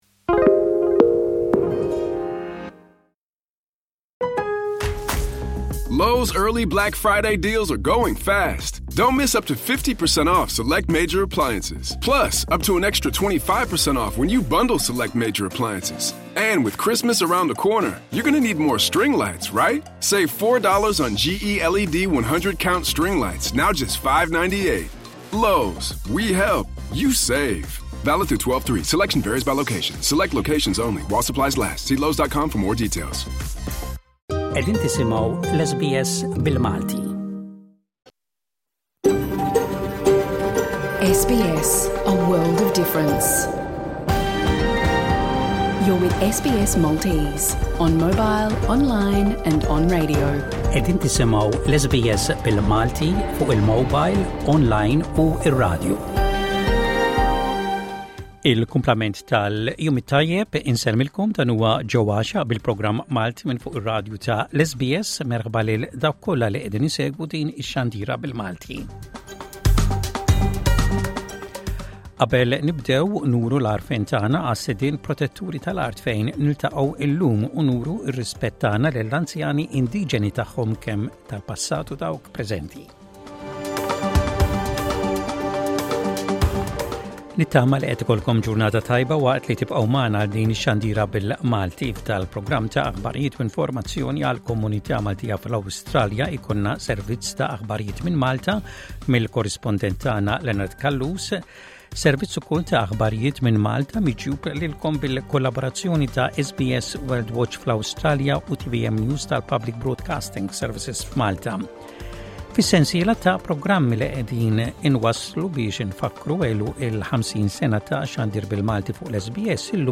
L-aħbarijiet mill-Awstralja u l-kumplament tad-dinja
Avviżi komunitarji.